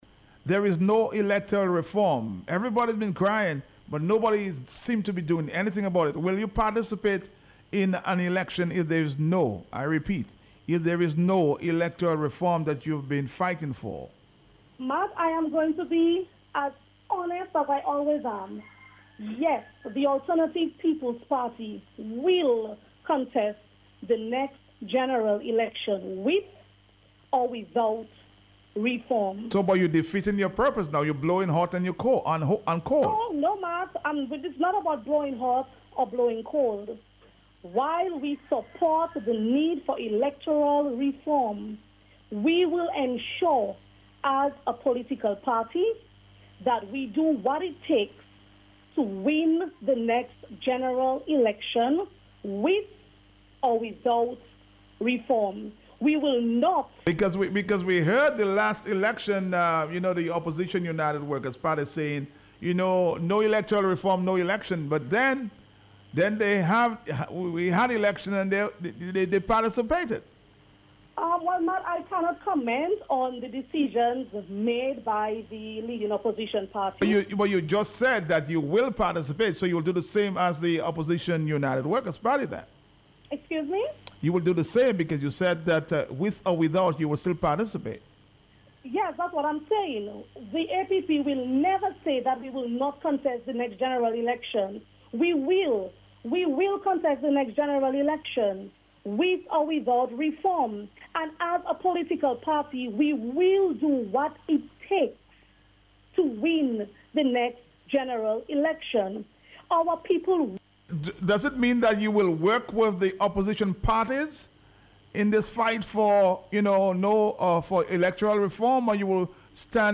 Find attached the interview.